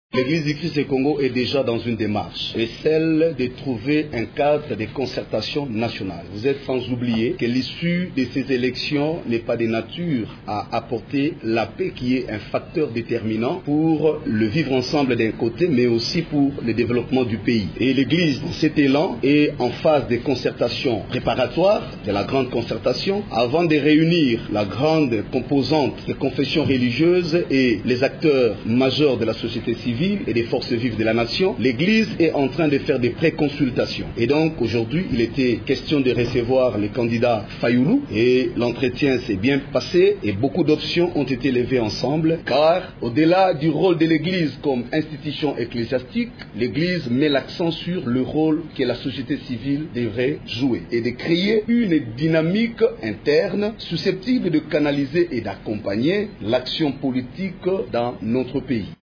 Au cours d’une conférence de presse animée à son siège, l’ECC précise que la série des consultations vise à trouver un cadre des concertations nationales, afin de « requalifier le rôle de la société civile et de canaliser une dynamique susceptible d’accompagner l’action politique en RDC. »